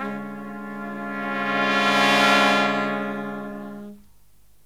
LONG HIT09-L.wav